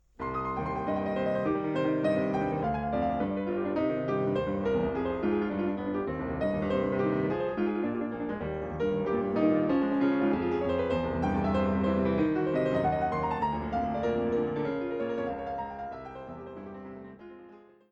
Étude des deux mains pour parcourir le clavier avec netteté.